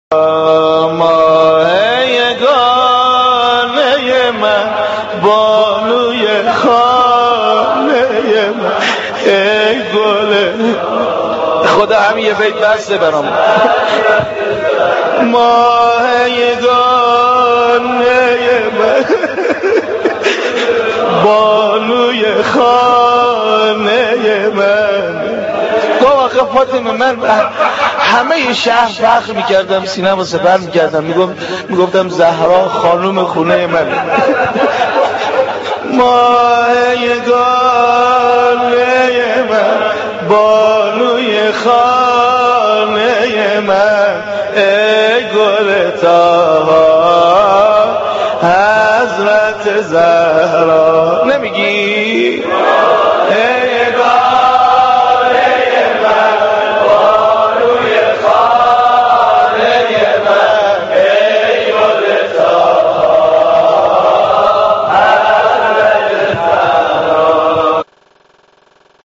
دانلود مداحی بانوی خانه من حضرت زهرا - دانلود ریمیکس و آهنگ جدید
مجلس نوحه خوانی برای شهادت حضرت زهرا(س)